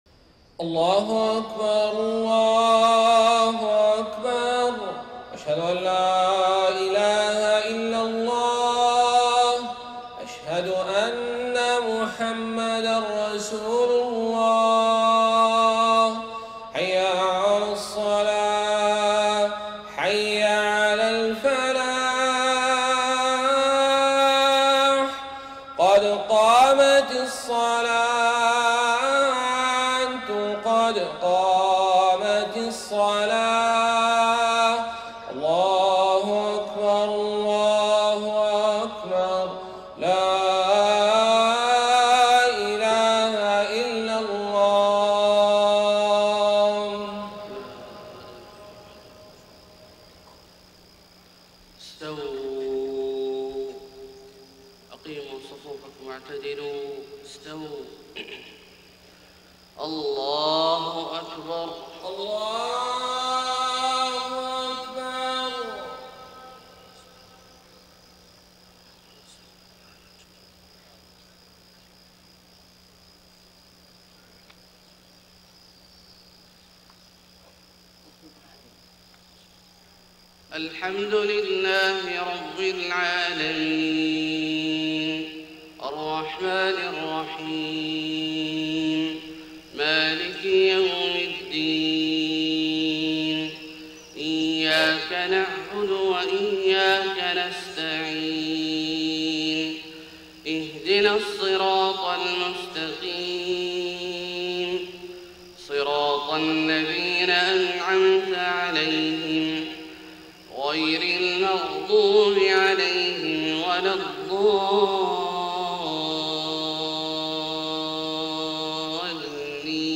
صلاة الفجر 6 ربيع الأول 1431هـ سورتي القيامة و الليل > 1431 🕋 > الفروض - تلاوات الحرمين